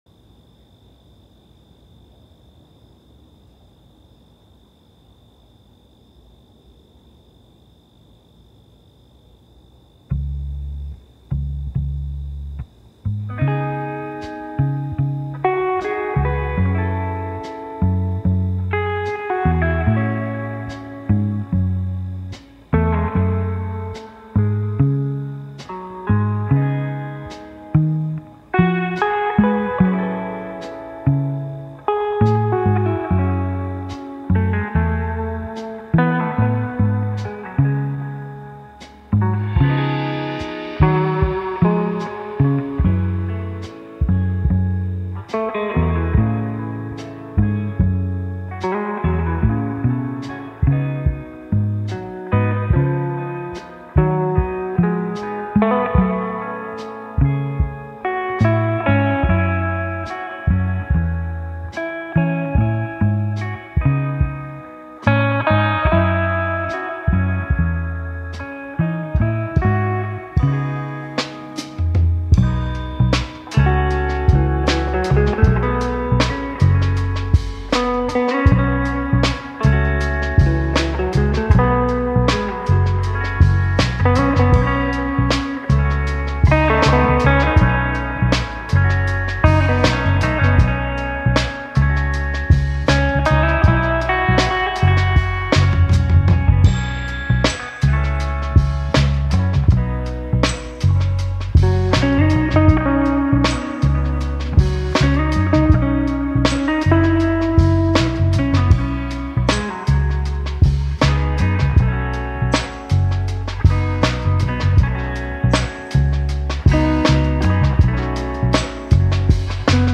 با صدایی فضایی و آرامش‌بخش
Psychedelic Rock, Ambient, Funk